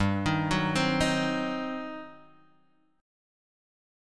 GM7sus4#5 Chord